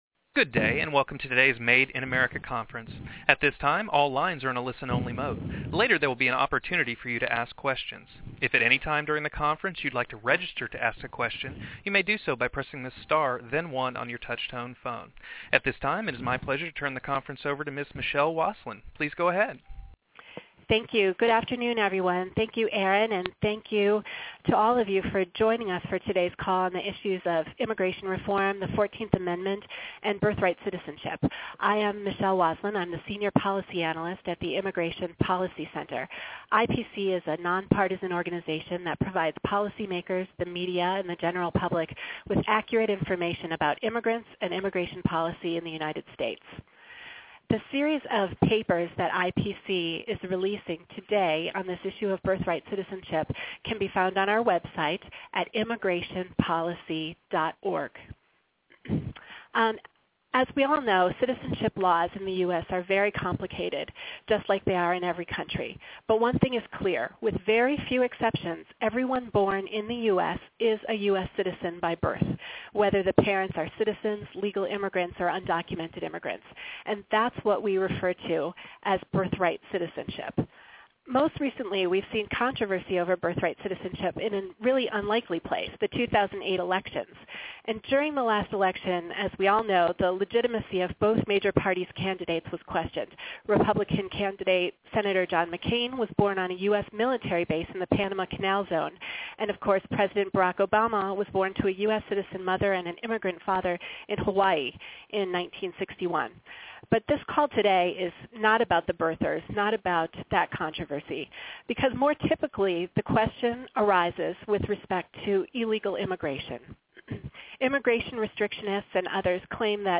WHAT: On-the-record, telephonic briefing with Q&A to release Made in America: Myths and Facts about Birthright Citizenship.